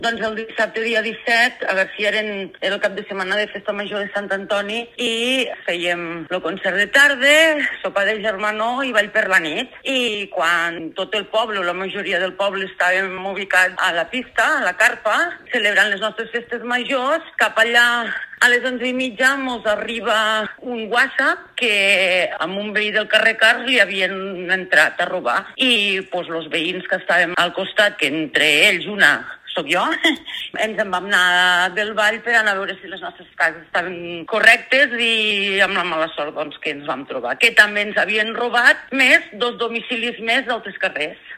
L’alcaldessa de Garcia, Blanca López, explica com van succeir els fets el dissabte 17 de gener